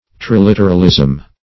\Tri*lit"er*al*ism\